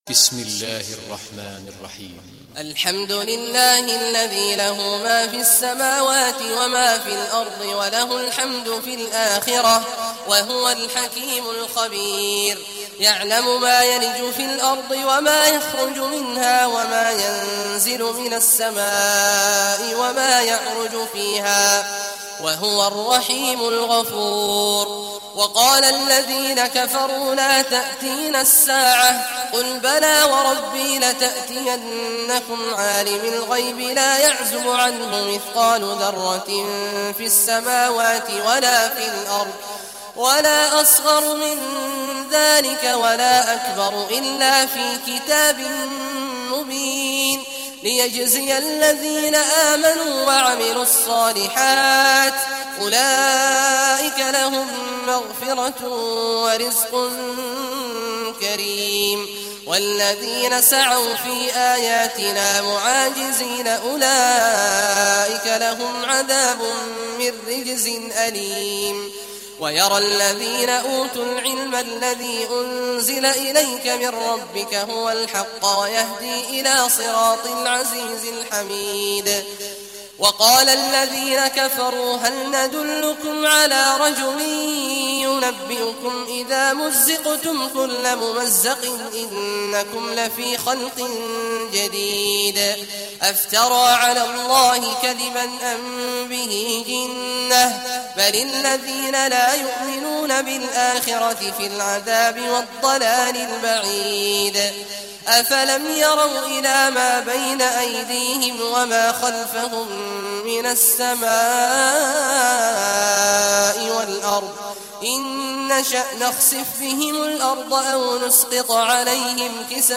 Surah Saba Recitation by Sheikh Awad al Juhany
Surah Saba, listen or play online mp3 tilawat / recitation in Arabic in the beautiful voice of Sheikh Abdullah Awad al Juhany.